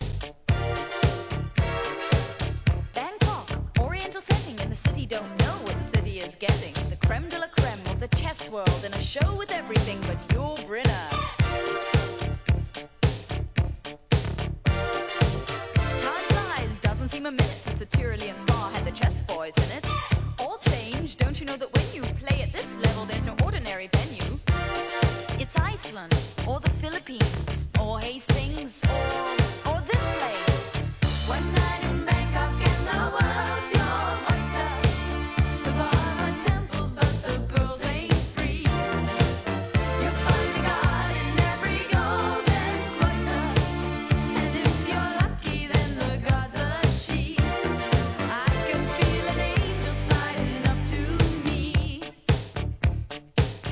店舗 ただいま品切れ中です お気に入りに追加 1984年大ヒットのエレクトロRAP調DISCO!!